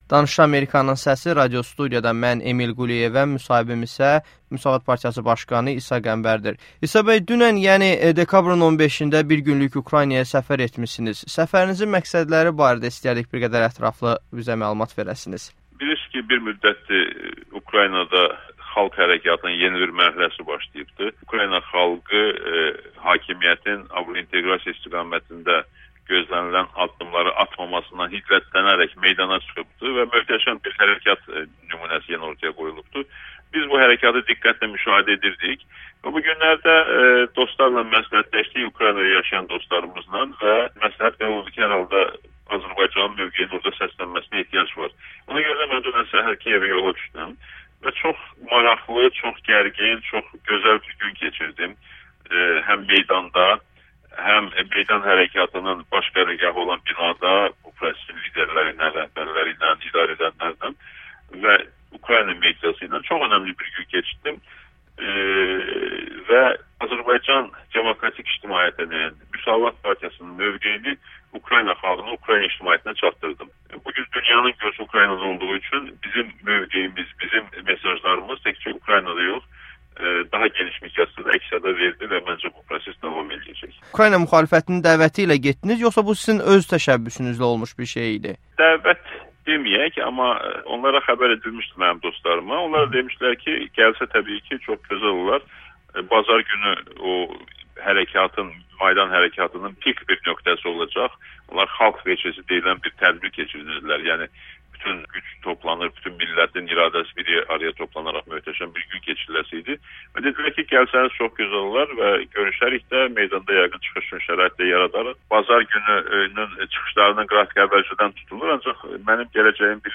İsa Qəmbər: "Maydanda Makkeynlə Azərbaycan barədə söhbətləşdik" [Audio-müsahibə]